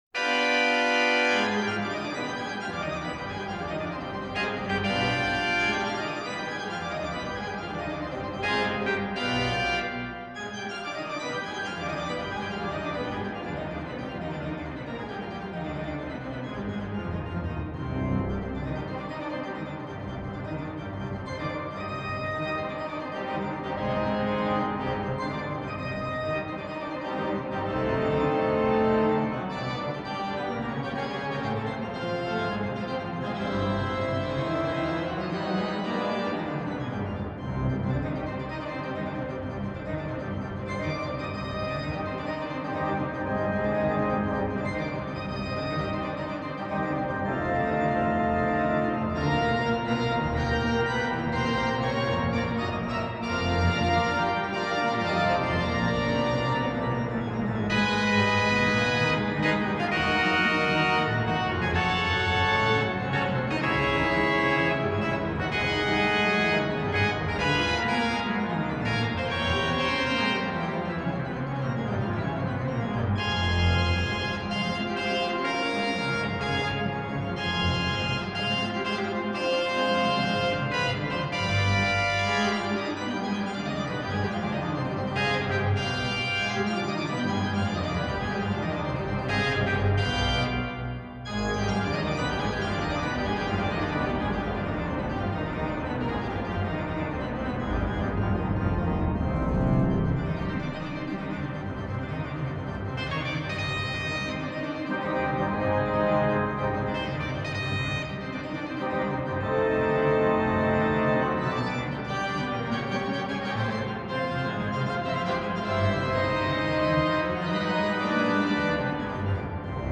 01. Etude, Op. 10, No 12 in C minor, 'The Revolutionary'.mp3